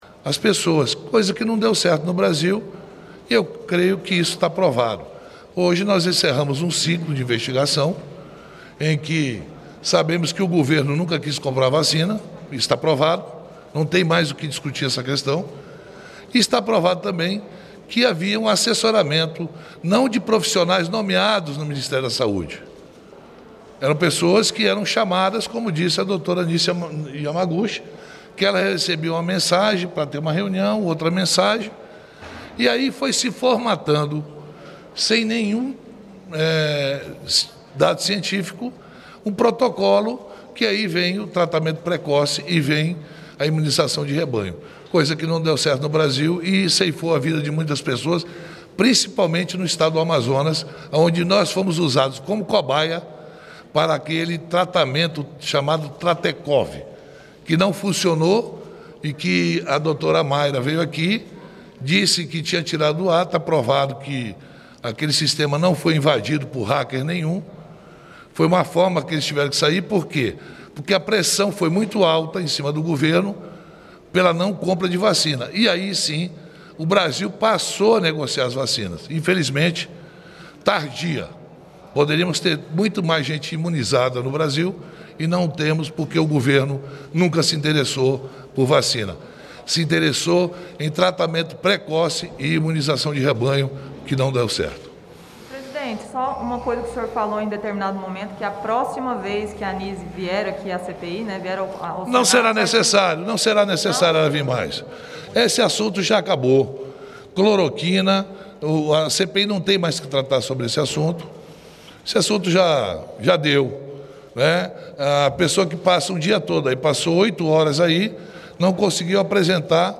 Entrevista coletiva com os senadores Omar Aziz, Renan Calheiros e Humberto Costa